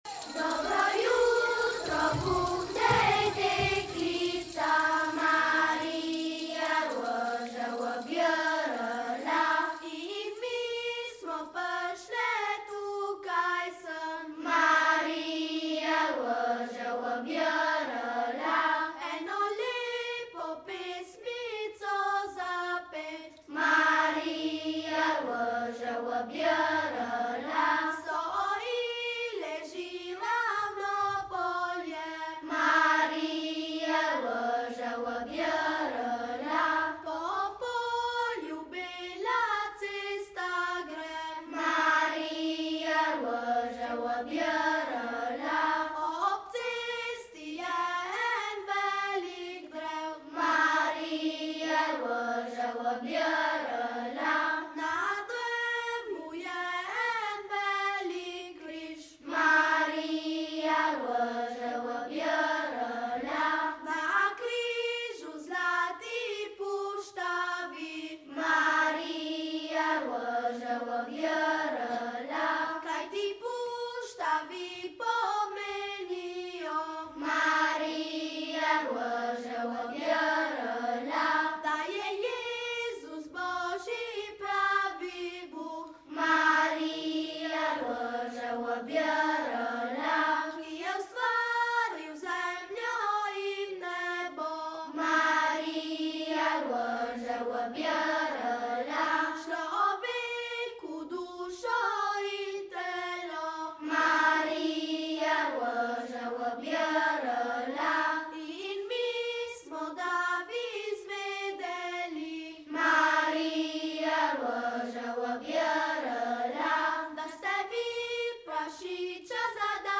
BOŽIČNE IN NOVOLETNE KOLEDNICE S TRŽAŠKEGA